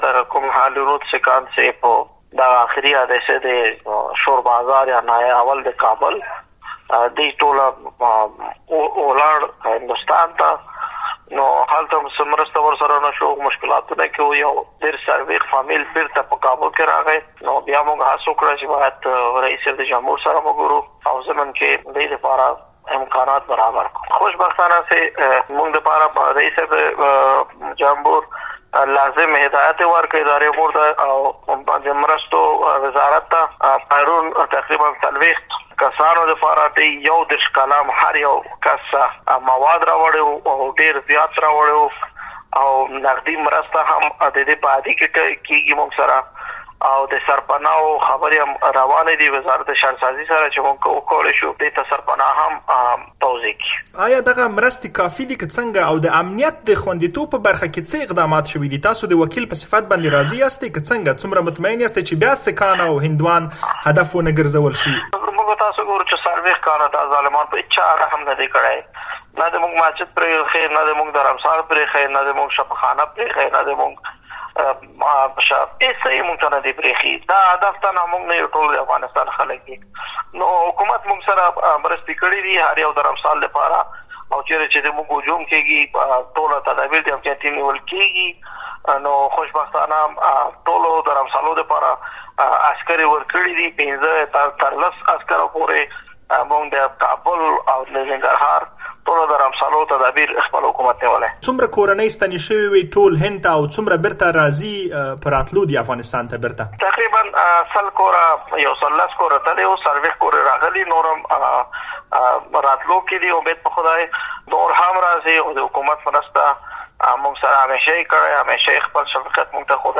افغان حکومت ډاډ ورکړی چې د سېکهانو او هندوانو امنیت به خوندي کړي. دا خبره په ولسي جرګه کې د سېکهانو او هندوانو استازي نرېندر سنګهـ خالصه ازادي راډیو ته وکړه.
له نرېندر سنګهـ خالصه سره مرکه